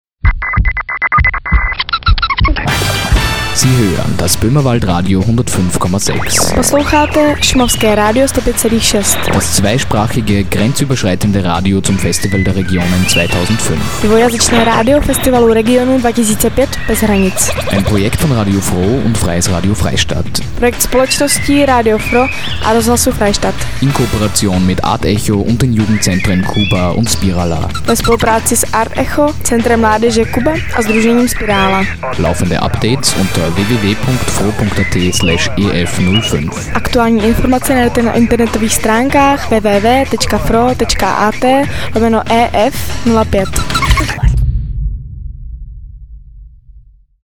senderkennung.mp3